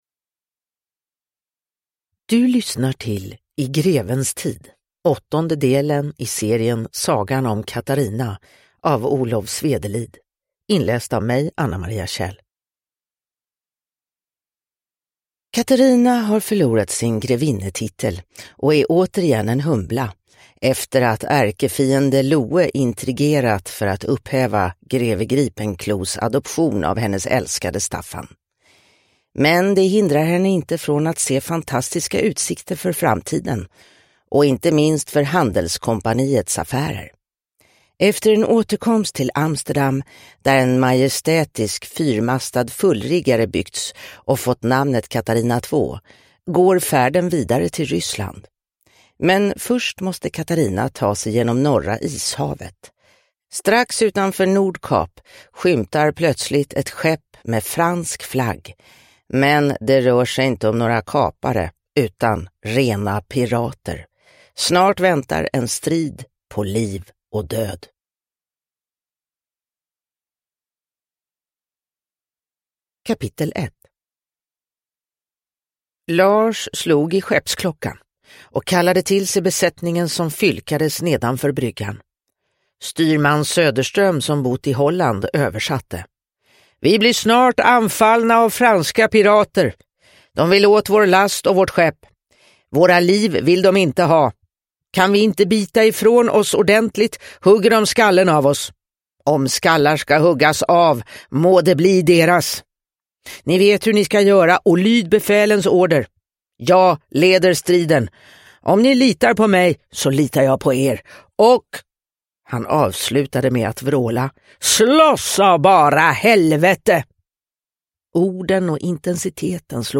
I grevens tid – Ljudbok – Laddas ner